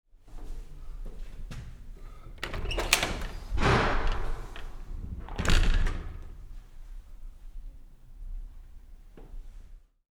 Toutefois, voici quelques exemples anonymes de sons qui ont accompagné un témoignage.
Courant-d-air.wav